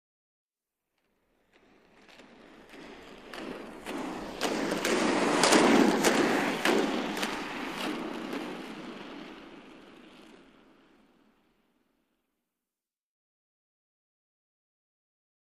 Roller Skating; Skating By.